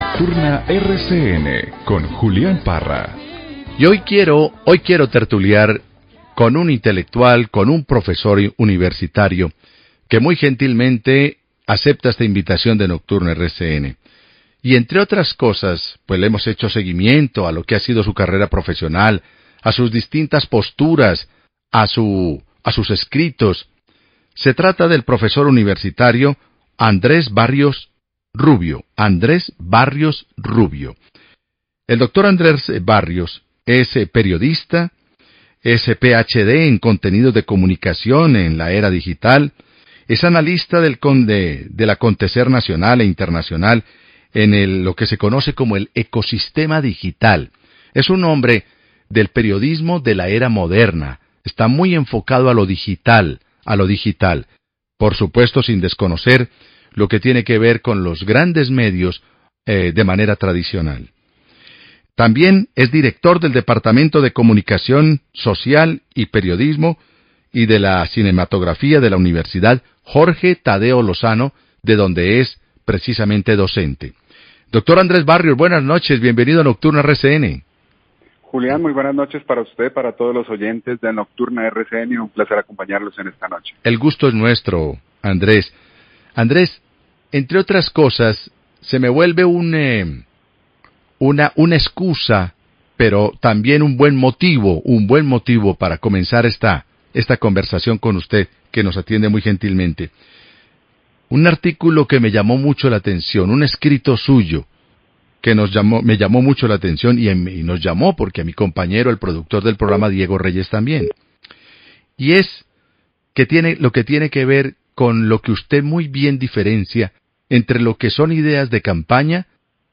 Escuche el audio de la entrevista en Nocturna de RCN Radio.